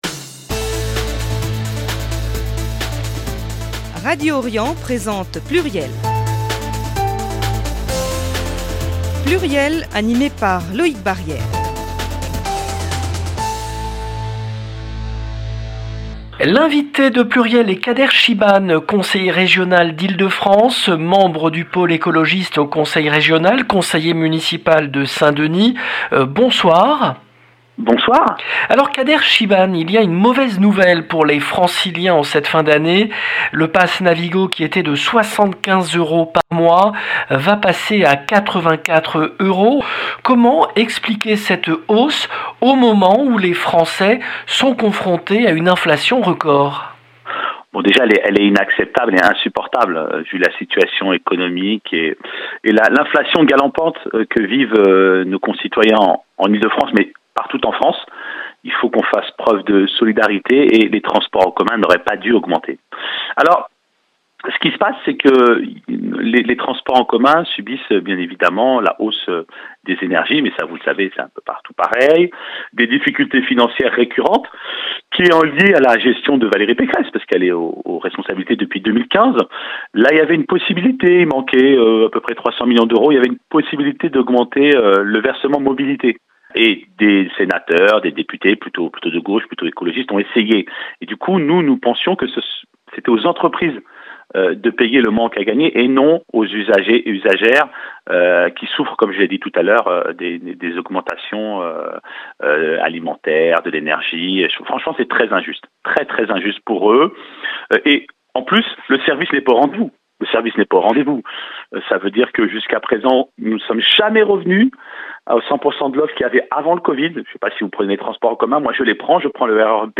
L’invité de PLURIEL est Kader Chibane , conseiller régional d’Ile-de-France, membre du Pôle Écologiste au Conseil régional, conseiller municipal de Saint-Denis